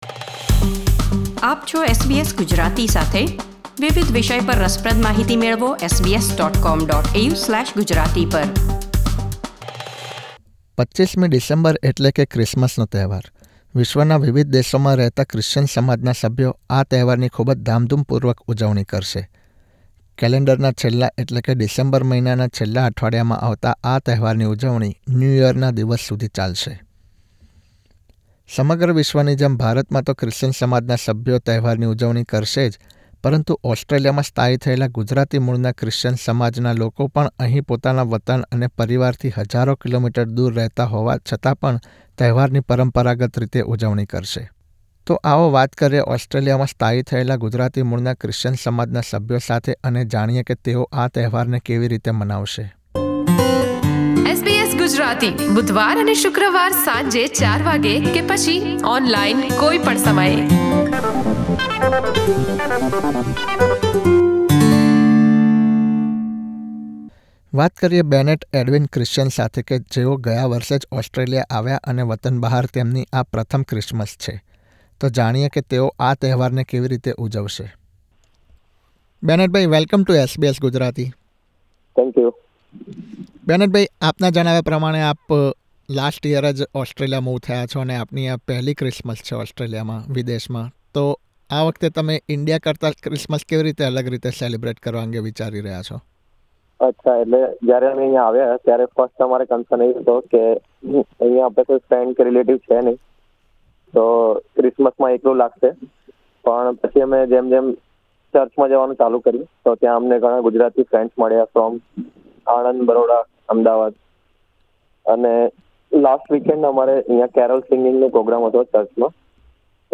તો પ્રસ્તુત છે ક્રિસમસના તહેવાર અને ઓસ્ટ્રેલિયામાં થનારી ઉજવણી અંગે તેમની સાથેની વાતચીત...